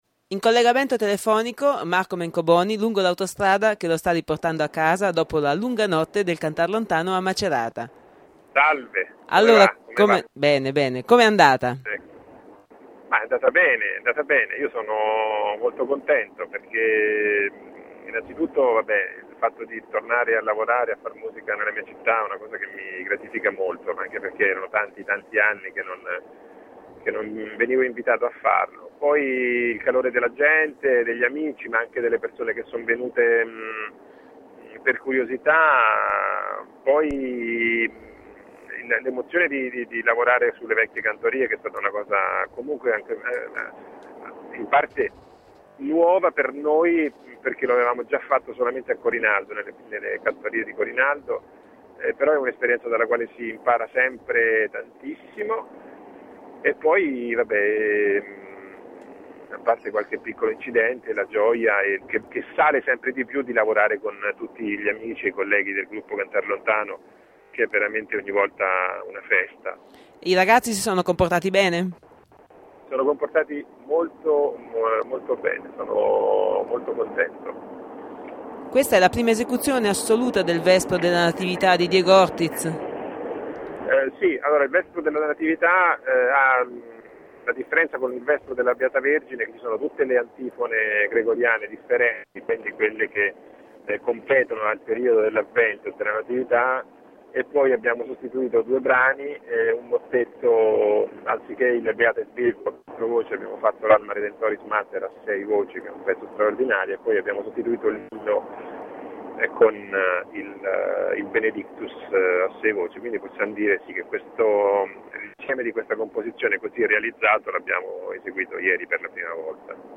Lo abbiamo beccato al telefono lungo la via del ritorno.